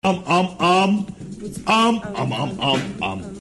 am am am am mellstroy Meme Sound Effect